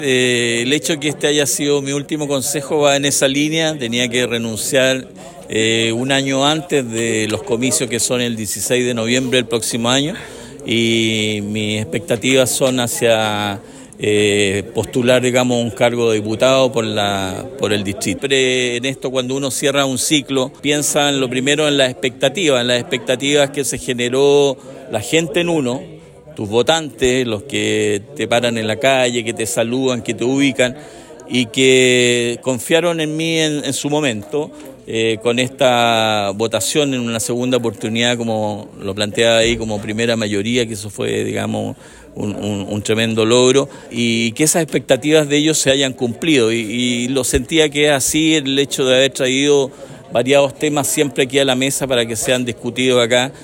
Este martes, Jorge Castilla asistió por última vez al Concejo Municipal de Osorno en su rol de concejal, luego de que anunciara su renuncia para iniciar un nuevo desafío en su carrera política: postular a un cargo parlamentario en las elecciones de 2025.